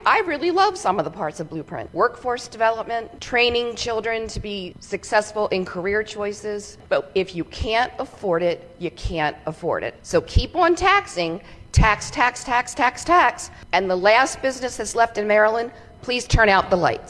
The budget has moved on from the House of Delegates to the Senate in Annapolis, but some lawmakers are still trying to make the point on the excessive costs of the Blueprint for Education.  Delegate April Rose who represents Carroll and Frederick Counties, spoke out about the increasing price and its effects on the state…